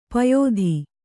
♪ payōdhi